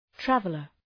{‘trævələr}
traveler.mp3